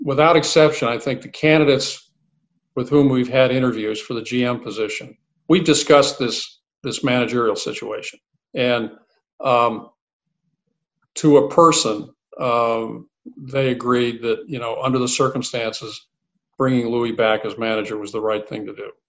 Sandy Alderson made 2 major announcements in his Zoom call with reporters yesterday–one was expected and the other was a bit surprising.